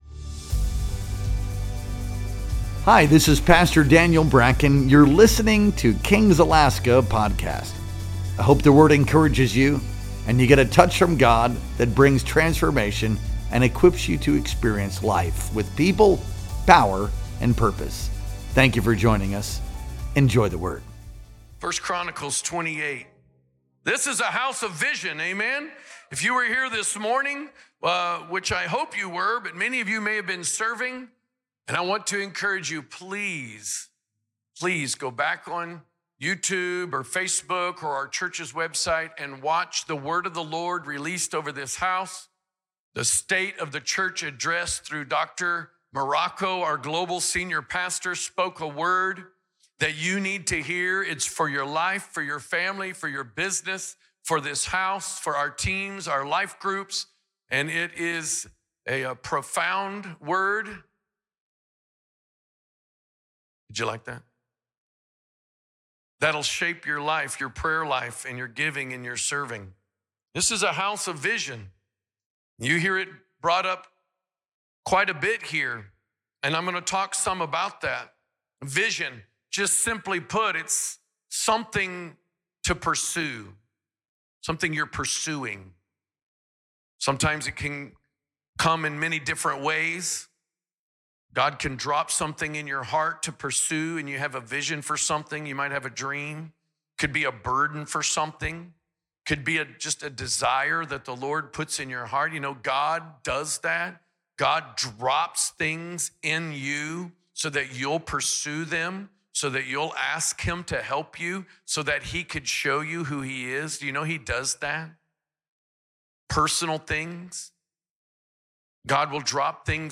Our Sunday Night Worship Experience streamed live on January 19th, 2025.